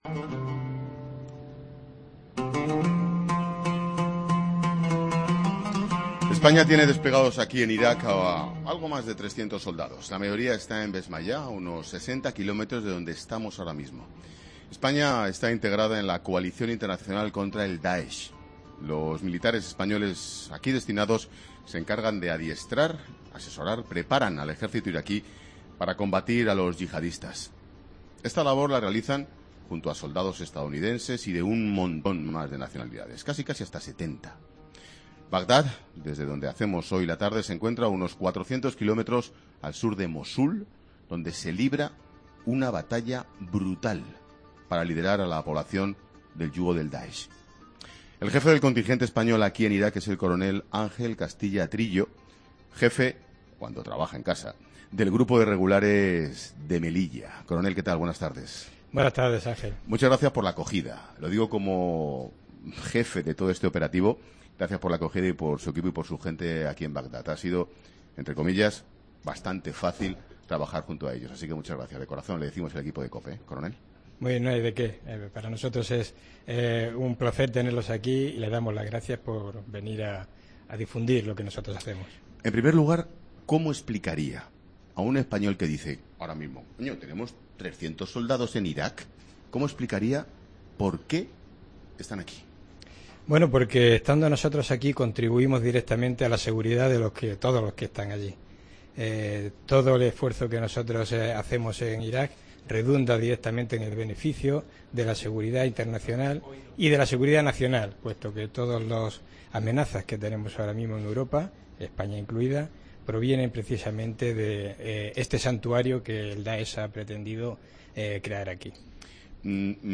Mariano Rajoy, presidente del Gobierno entra en el programa especial de 'La Tarde' desde Irak